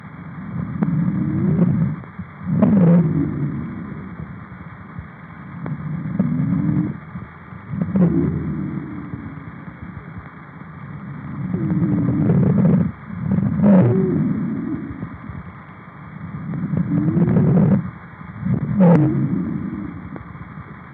Mitä löydöksiä kuulet oheisessa potilaalta rintakehän alaosasta taltioidussa hengitysääninäytteessä?
Myös sisäänhengityksessä on kuultavissa vinkunaa, joskin vaimeammin kuin uloshengityksessä.
Hengitysääninäytteessä kuultava vinkuna on korkeataajuisempaa kuin rohina.